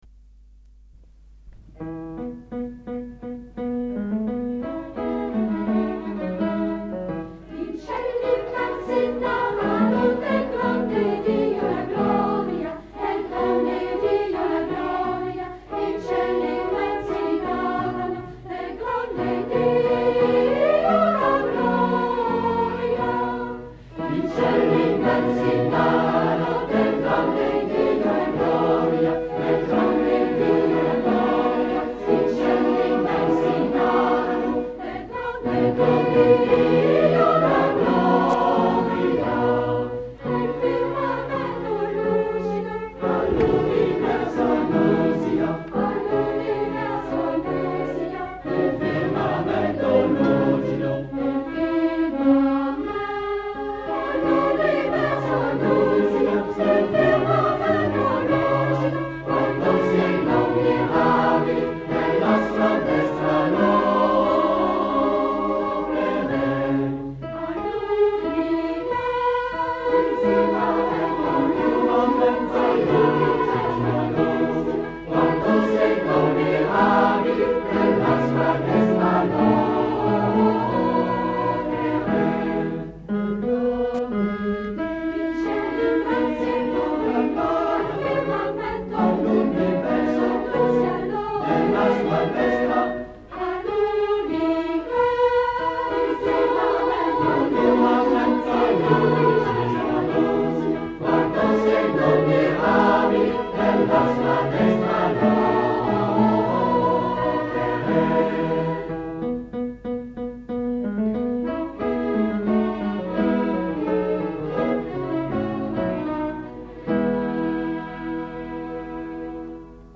Concert  ACJ Macon CAC 20h30 27 Mai 1988 MACON